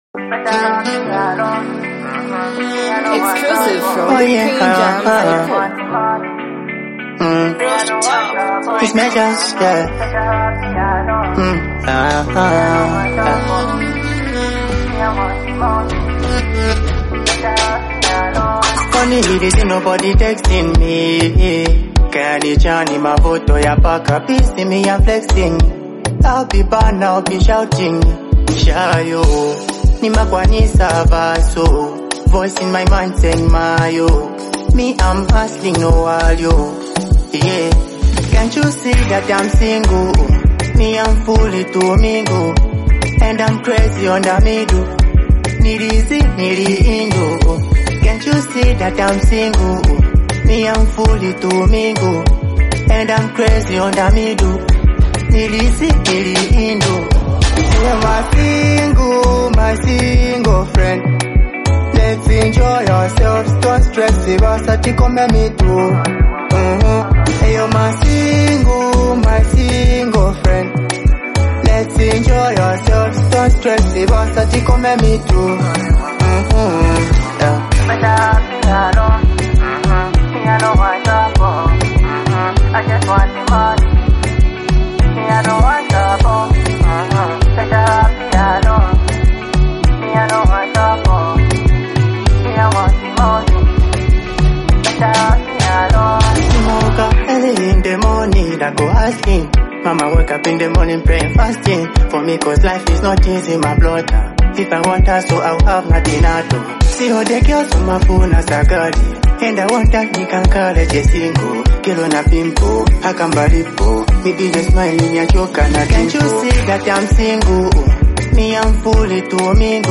The song carries an emotional yet confident tone